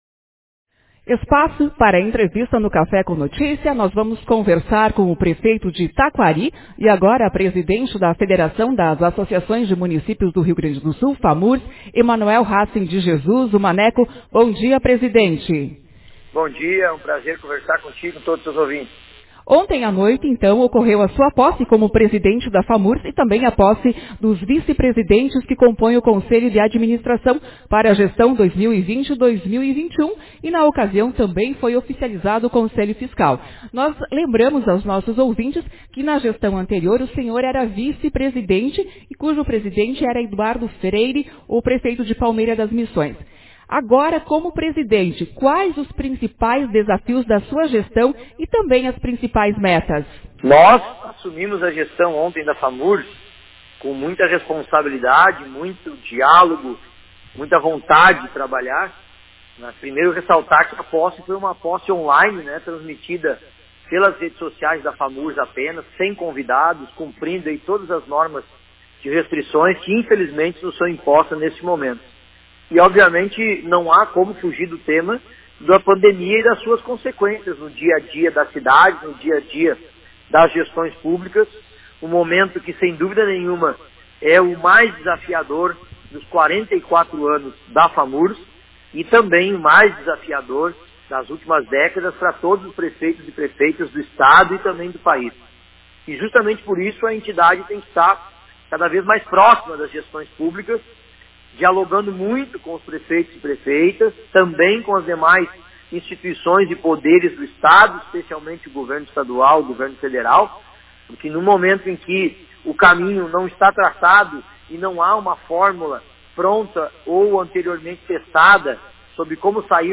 Toma posse o novo presidente da Famurs-Federação das Associações de Municípios do Rio Grande do Sul Autor: Rádio Marabá 09/07/2020 0 Comentários Manchete Na manhã desta quinta-feira,09, no programa Café com Notícias, o novo presidente da Famurs, Emanuel Hassen de Jesus, prefeito de Taquari, concedeu entrevista e na ocasião, dentre vários assuntos, falou sobre o trabalho que pretende realizar frente a Federação.